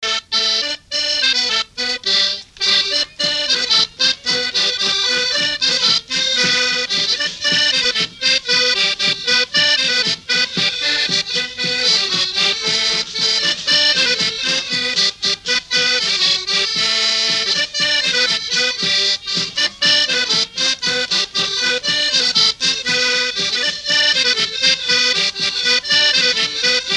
danse : branle : courante, maraîchine
Pièce musicale inédite